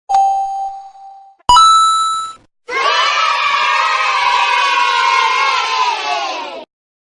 KFmbBY0uyAD_efecto-de-sonido-bien-win-ganador-acierto.mp3